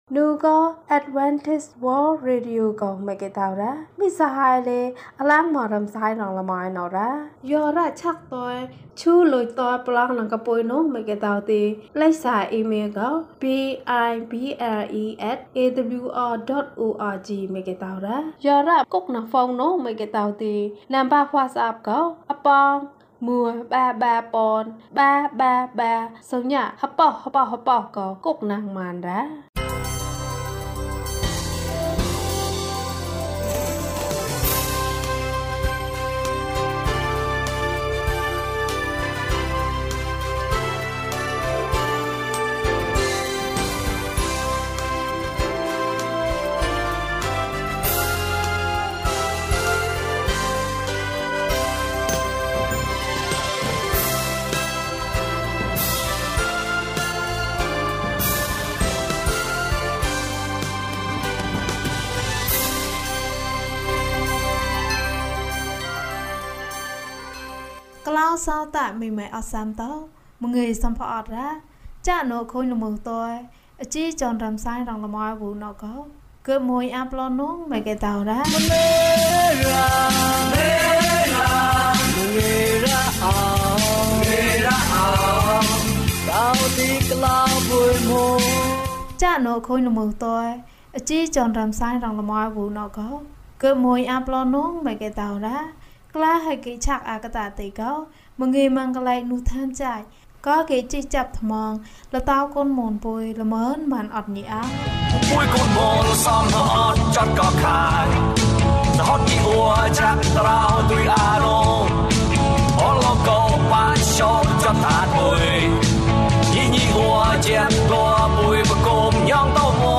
အဘိုးအိုကိုကူညီပါ။ ကျန်းမာခြင်းအကြောင်းအရာ။ ဓမ္မသီချင်း။ တရားဒေသနာ။